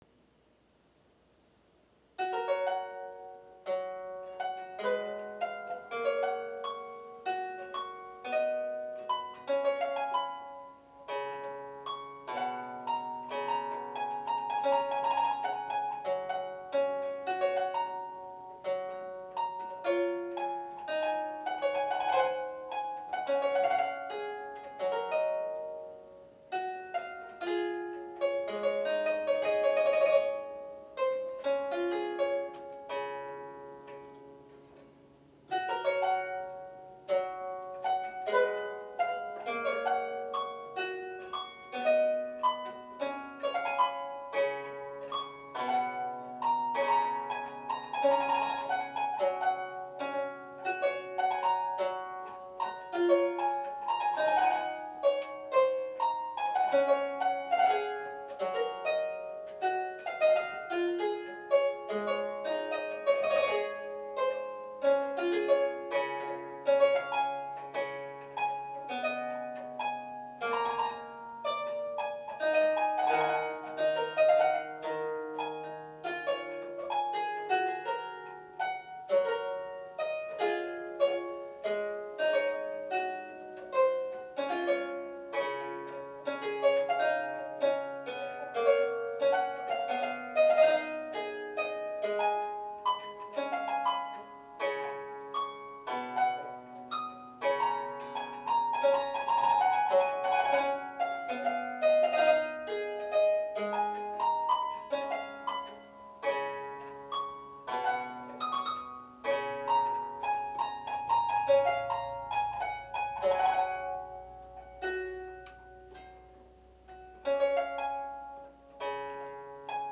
3' Ottavino Lautenwerk - 2x4' featuring an unprecedented action consisting of one rank of jacks plucking one or both choirs with single plectra.